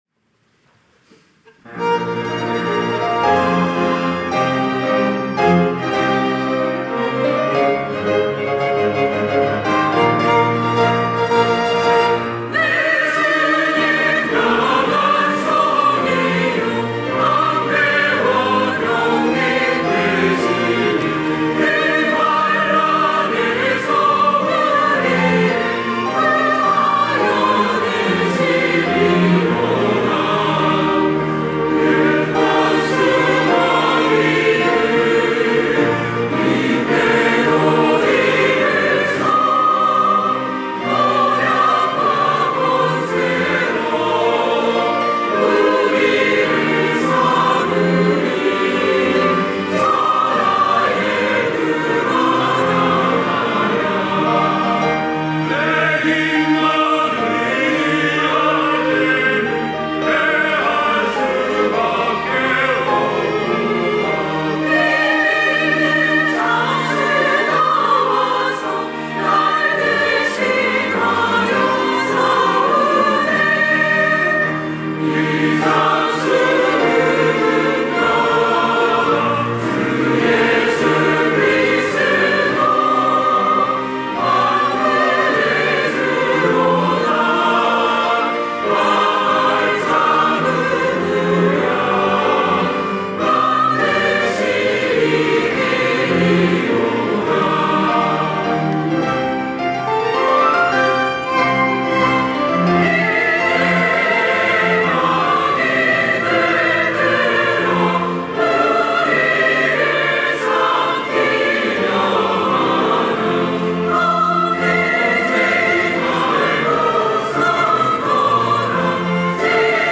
호산나(주일3부) - 내 주는 강한 성이요
찬양대 호산나